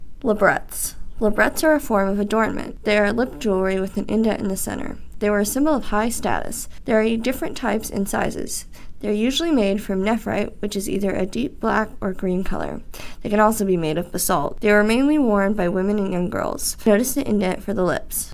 The audio guide to the kits is provided below.